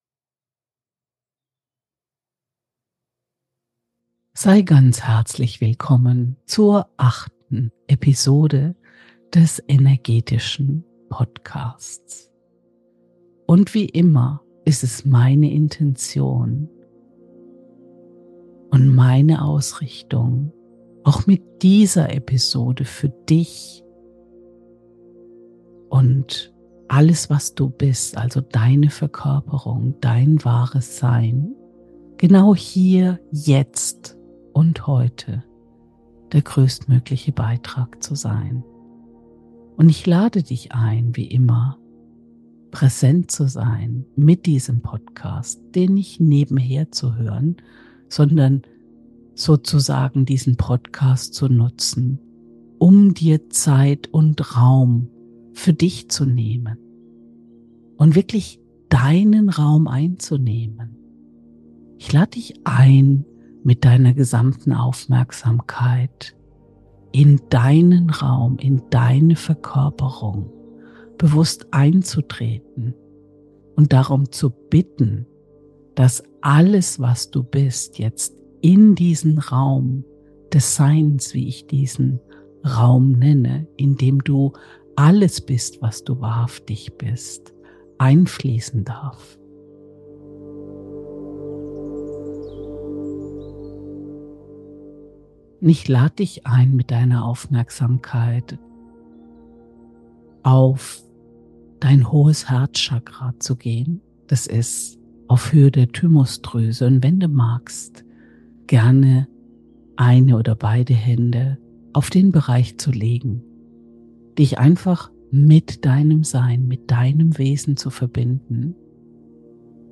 Beschreibung vor 1 Jahr In der 8. Episode, des live im ewigen Schöpfungsmoment des Jetzt, aufgenommenen Energetischen PODCASTS, bist du wieder eingeladen, eine weitere transformative Erfahrung zu machen.